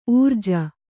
♪ ūrja